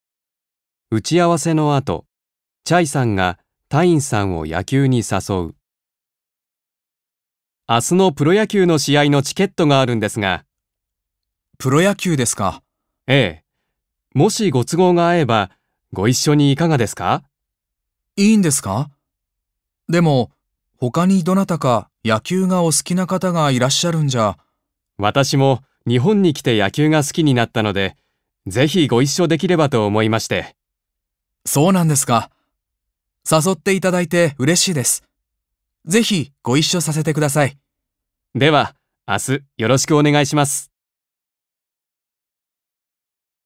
1. 会話（誘う・誘いを受ける